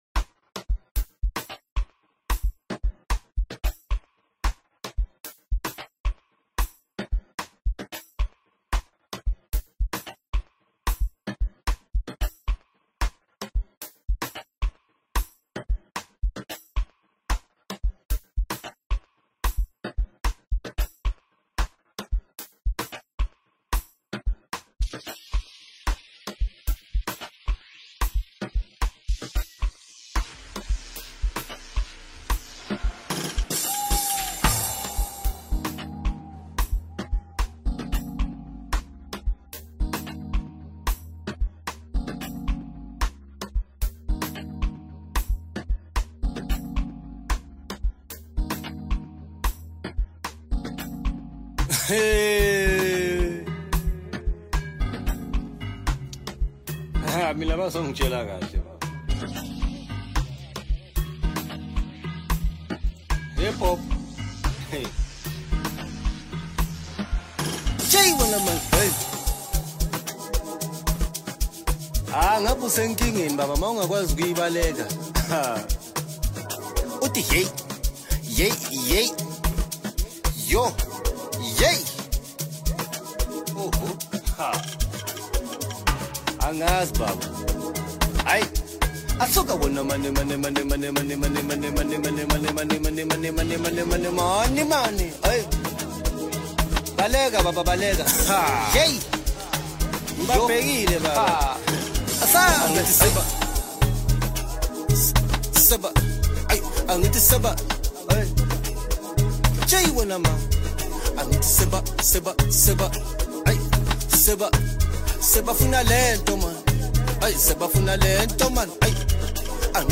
Home » Amapiano » Deep House » Hip Hop » Latest Mix
superb instrumental arrangement
filled with passion, vibrant energy